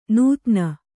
♪ nūtna